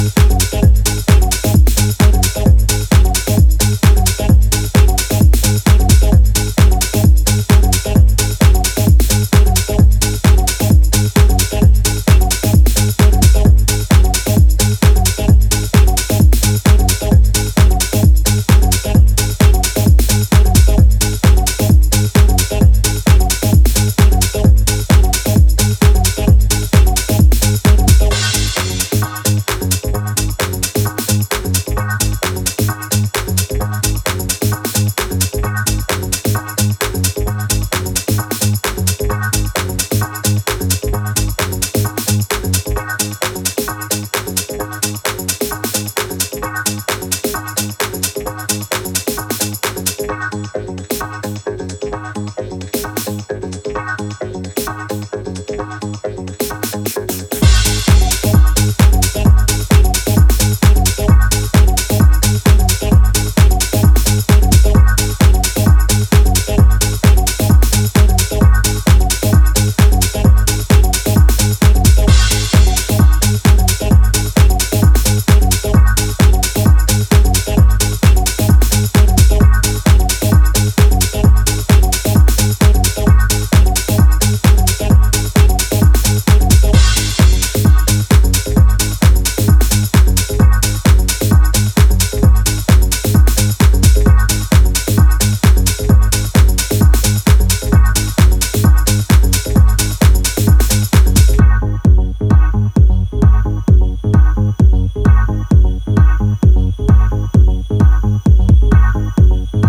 Dancefloor killers.